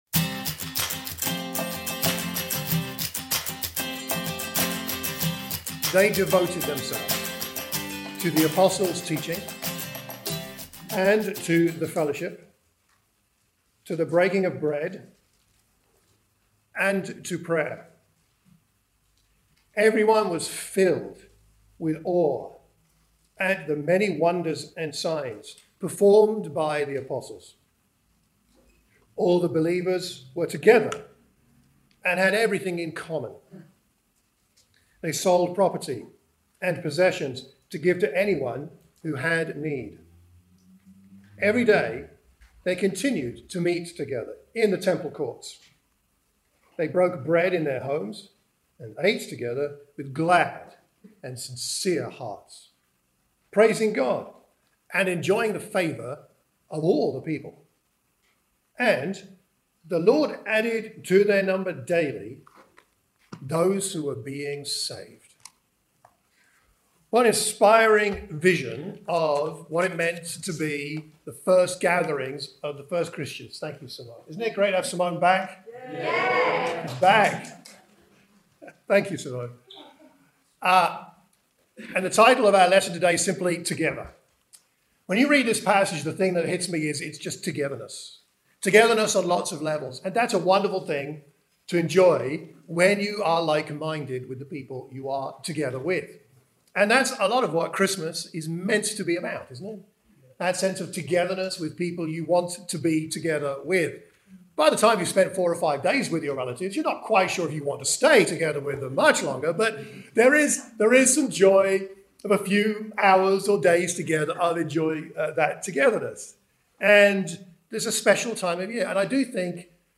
In this sermon for the Watford church of Christ we examine the healthy practices of the early church.